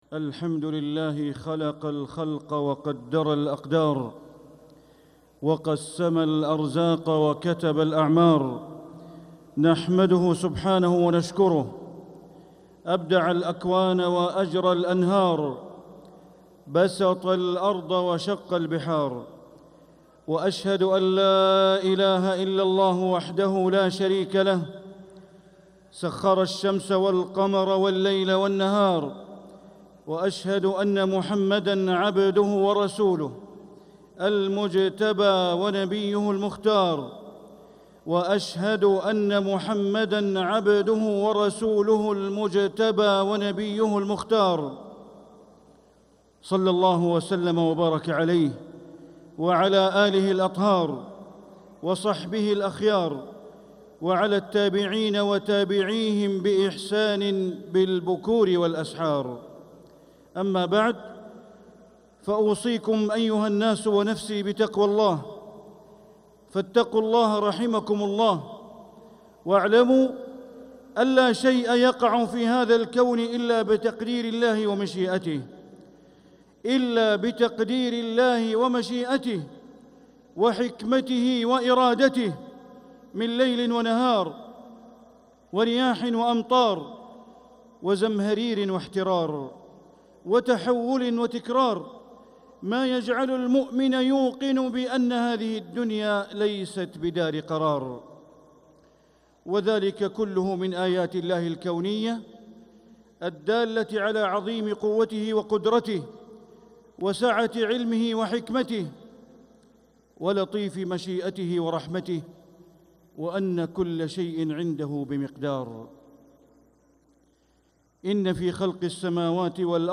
خطبة الجمعة 21 صفر 1447هـ > خطب الشيخ بندر بليلة من الحرم المكي > المزيد - تلاوات بندر بليلة